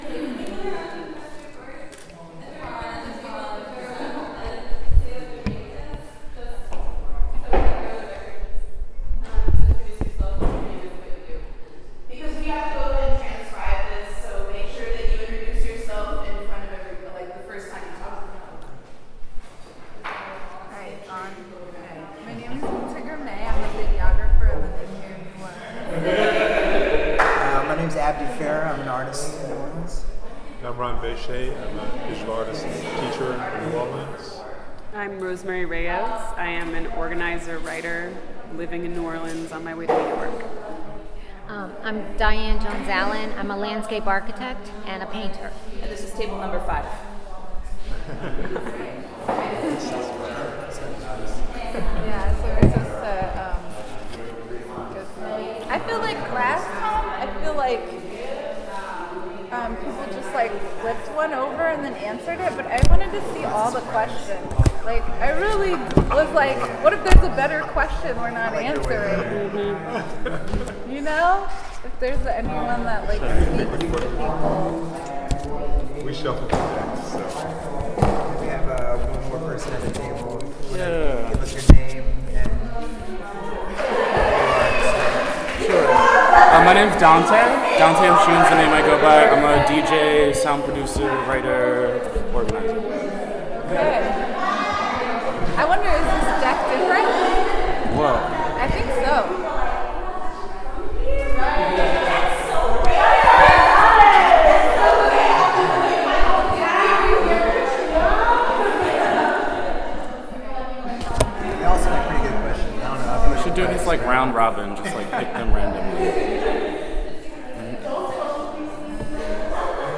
sound recording-nonmusical
Genre oral history